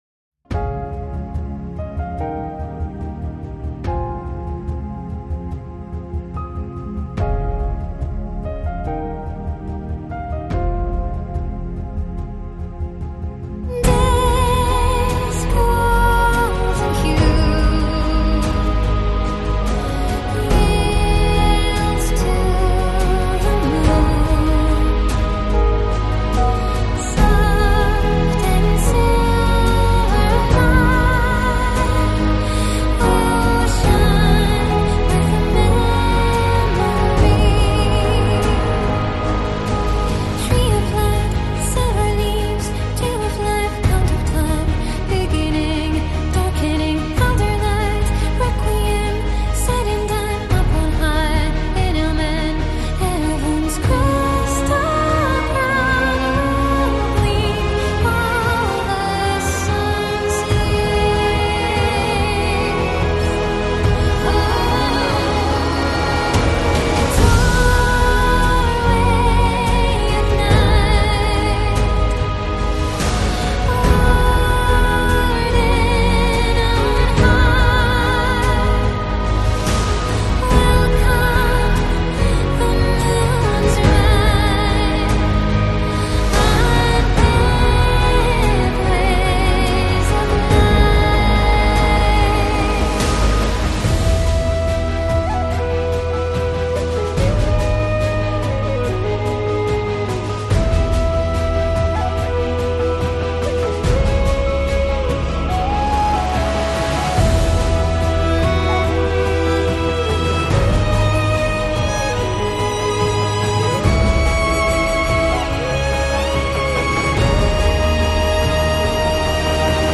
ambient, celtic, epic, new age, soundtrack
缥缈和交响电影配乐般的音乐-在听众的脑海中展开史诗和优雅的故事场景
vocals